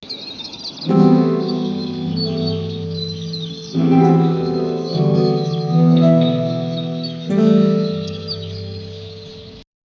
Amusement Sound Effects - Free AI Generator & Downloads
create-ambient-sound-for--zzchrprd.wav